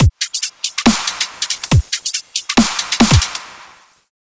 Drumloop_01.wav